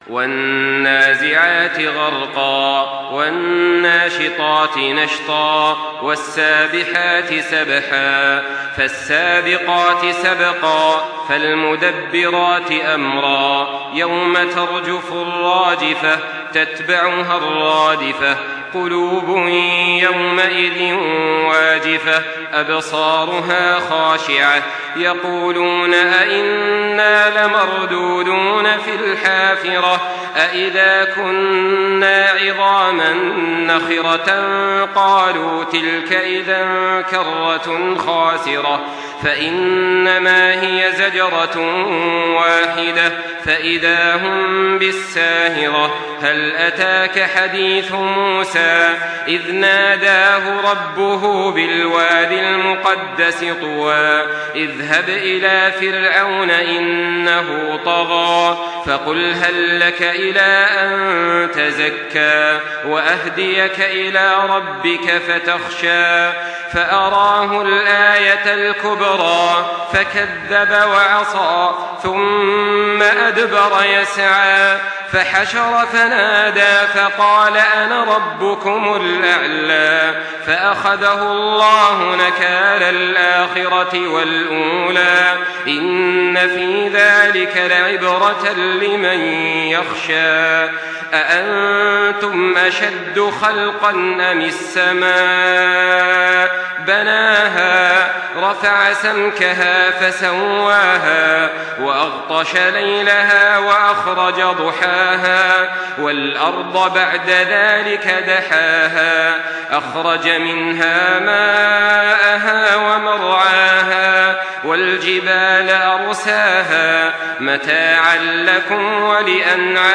Makkah Taraweeh 1424
Murattal